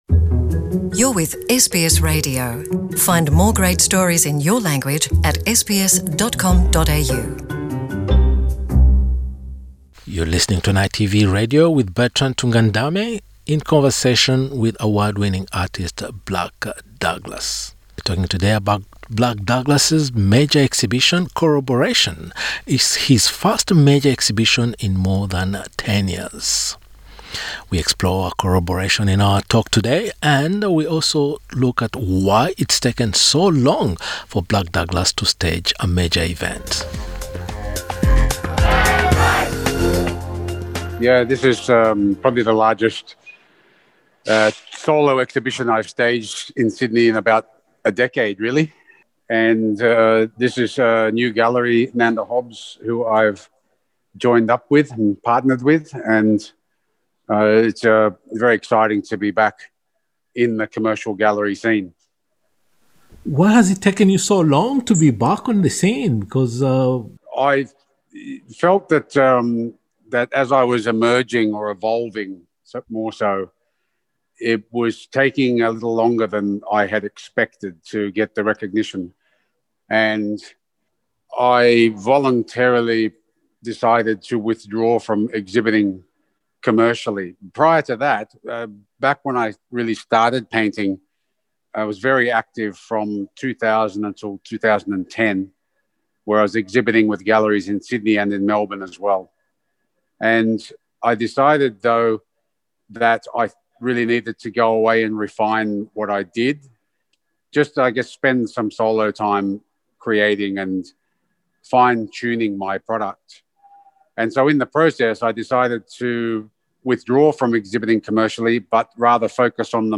Currently on display at Nanda Hobbs Gallery in Sydney, Corroboration Nation is Blak Douglas’s first major exhibition in about a decade. Talking to NITV Radio, the artist explained that his long absence from the exhibition’s scene was voluntary.